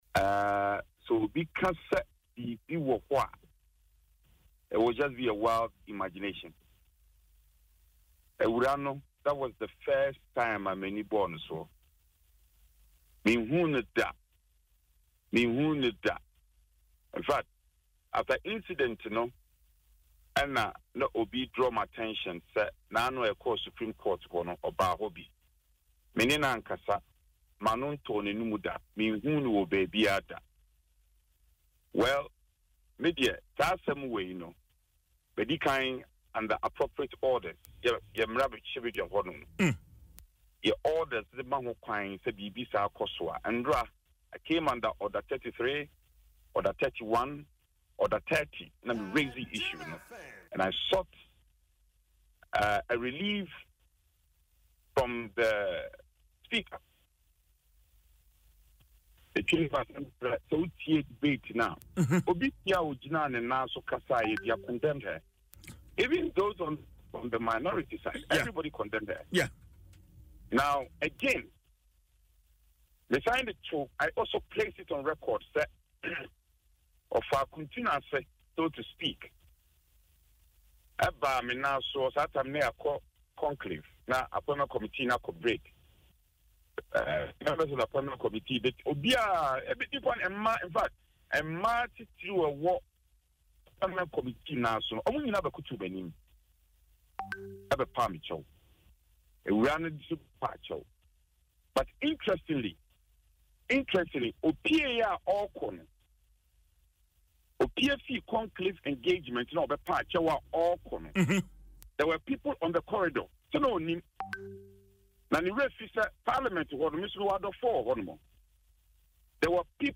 In an interview with Adom FM’s Dwaso Nsem, Mr. Annoh-Dompreh explained that it was Hardcastle’s actions after the apology that led him to refer the matter to Parliament.